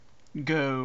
vad-go-mono-32000.wav